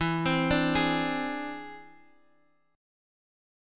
E+7 chord